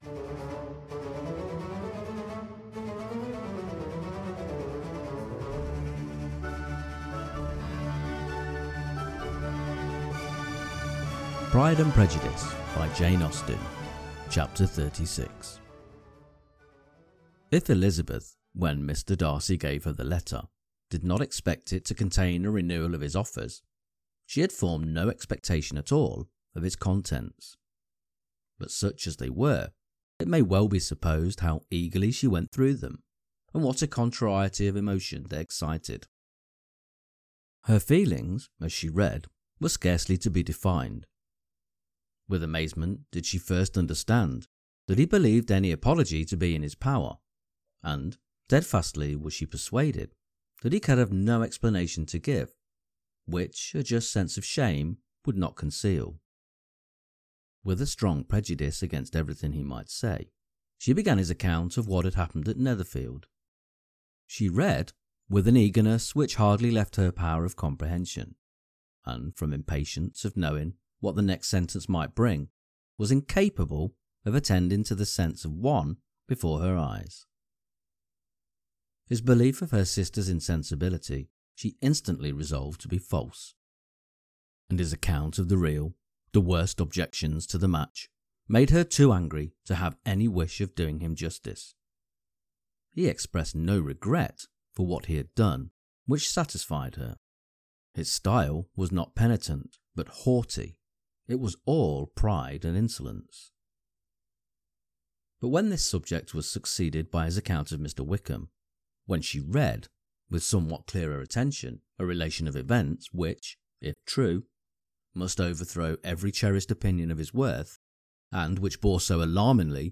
Pride and Prejudice – Jane Austen Chapter 36 Narrated - Dynamic Daydreaming